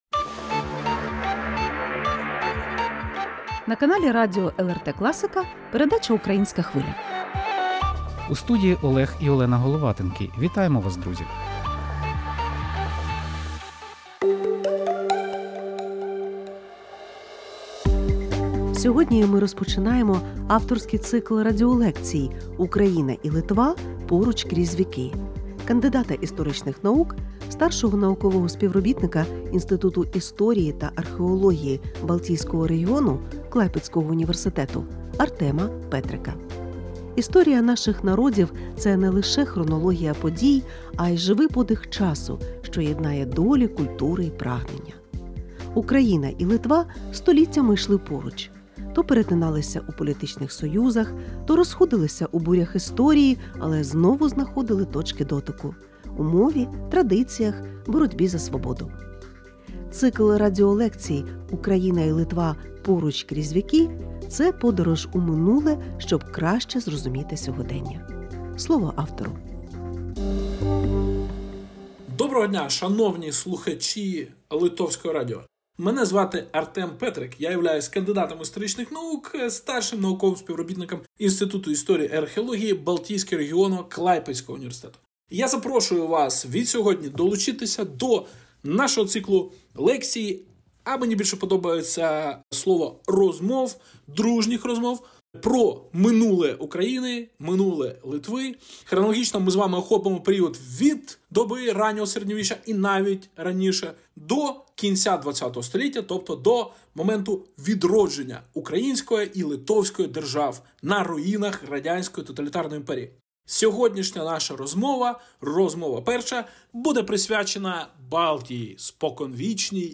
Радіолекція